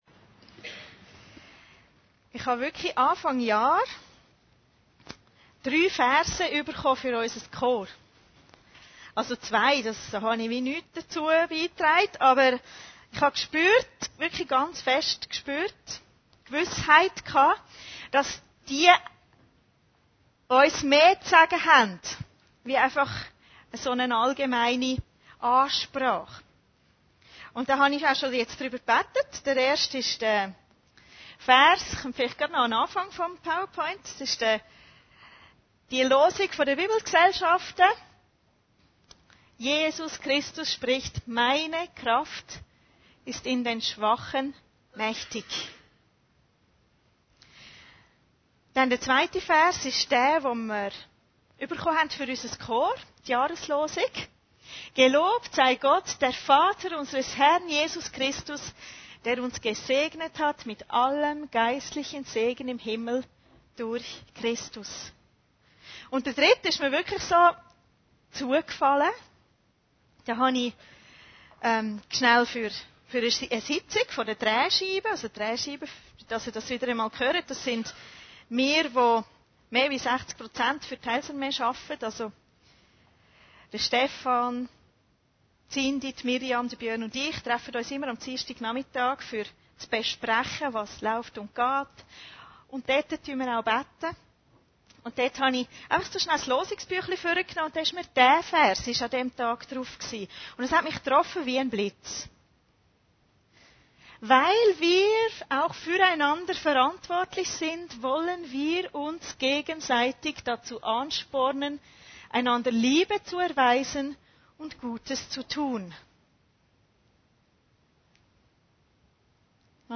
Predigten Heilsarmee Aargau Süd – Gemeinsam sind wir stark